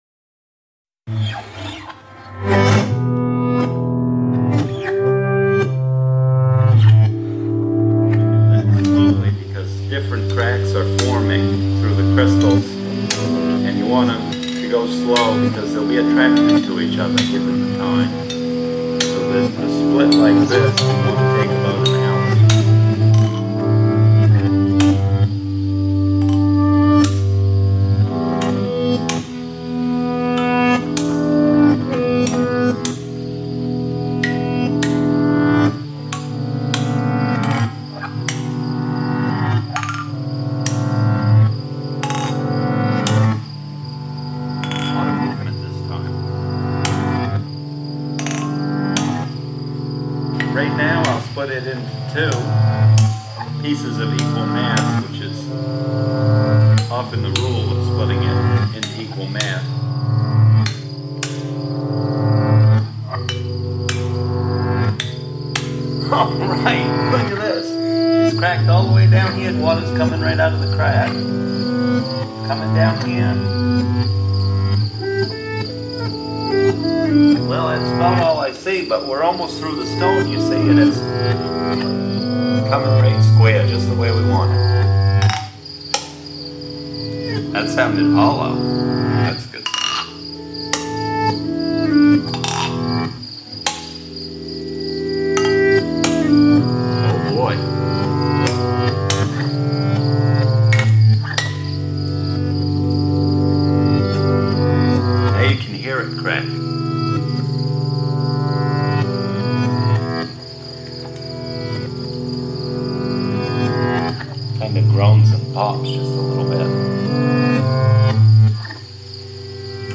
reimagines his own field recording from a rock quarry in Maine.